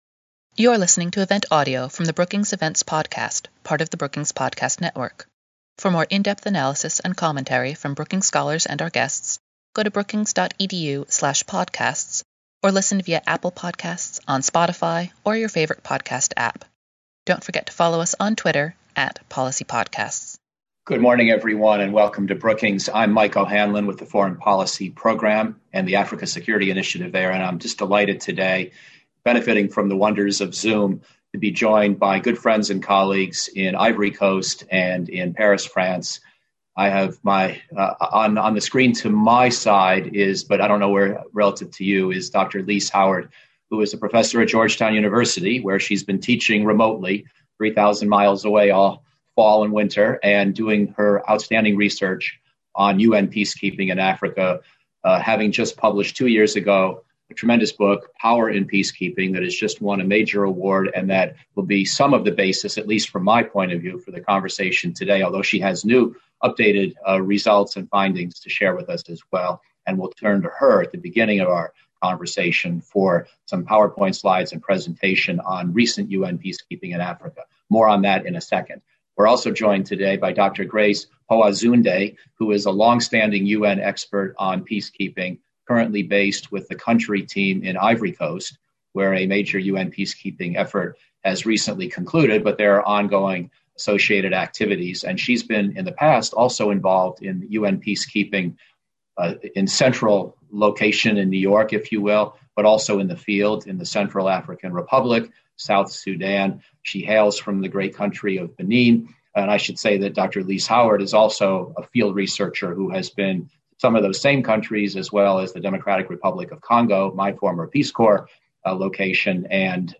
Brookings Senior Fellow Michael O’Hanlon moderated the conversation.